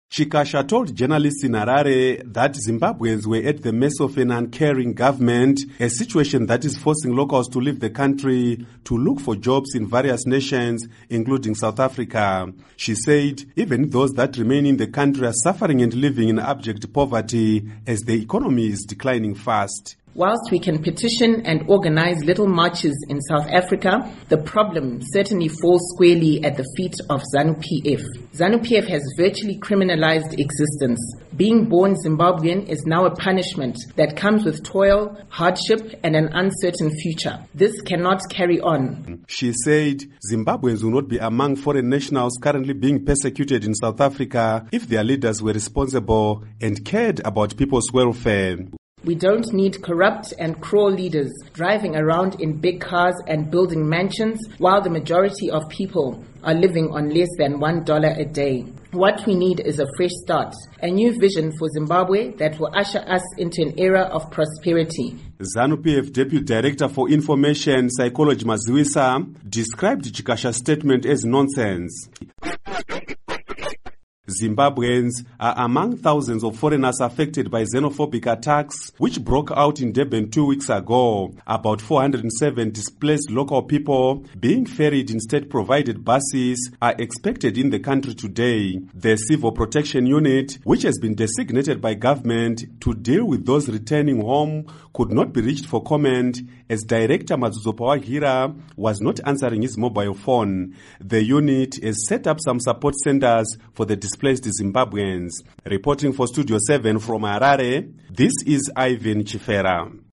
Report on Xenophobic Attacks